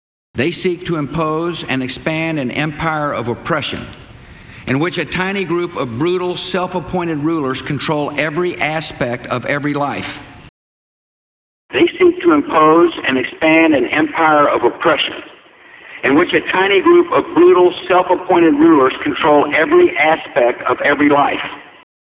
It’s clear that the two vocals come from two different sources.
I used a Yeti to re-record my parts, and the subject was recorded on the laptop’s mic, I know I can’t achieve a completely uniform sound.